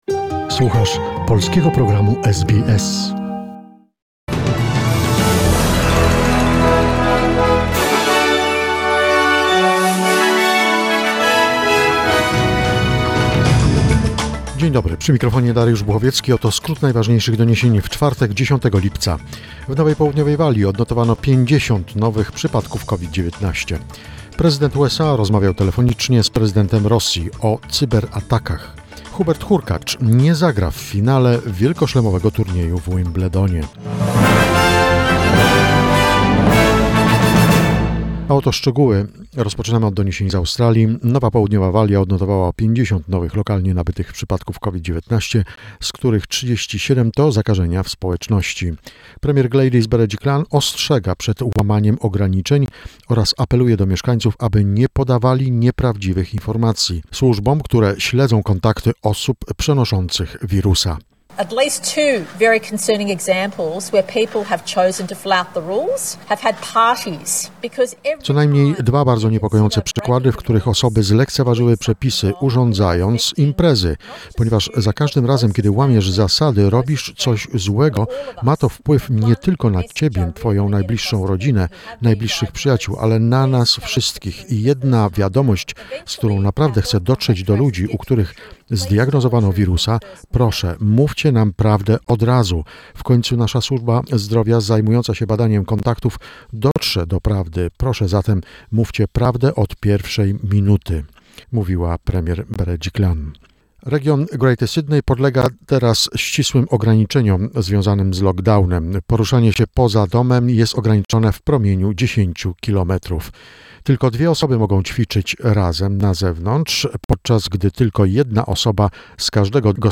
SBS News Flash in Polish, 10 July 2021